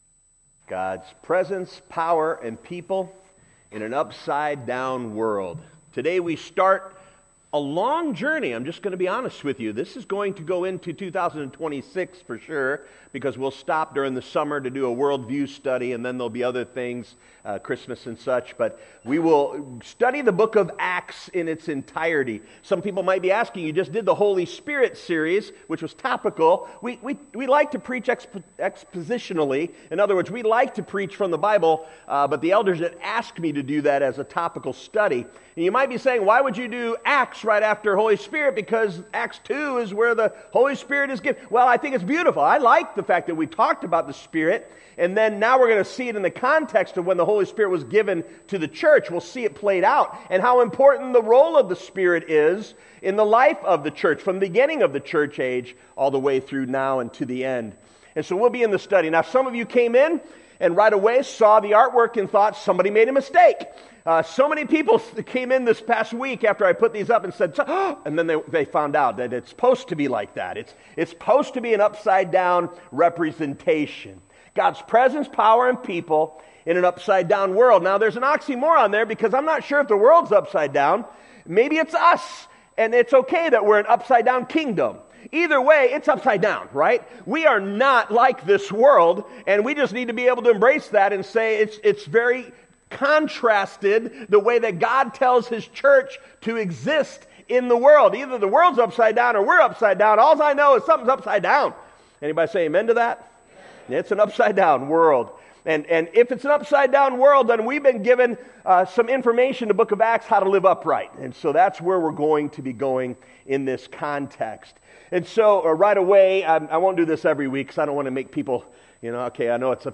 In this sermon on Acts